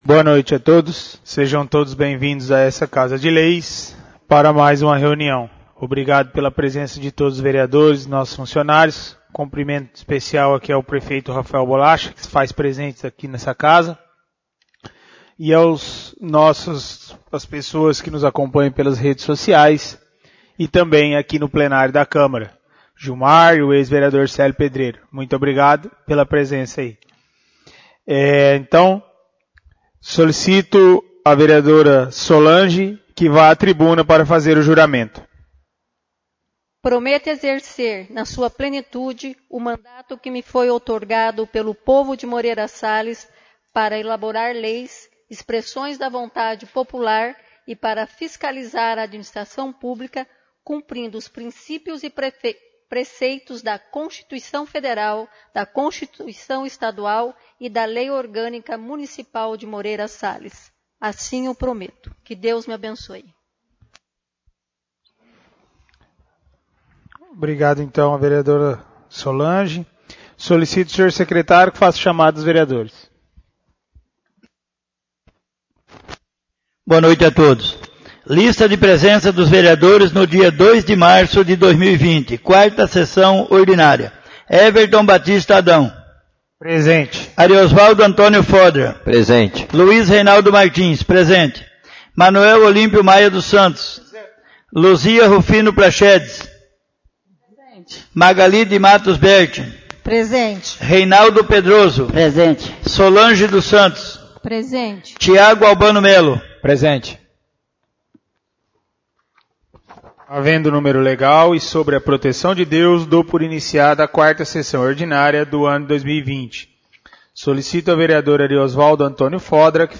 4° Sessão Ordinária — CÂMARA MUNICIPAL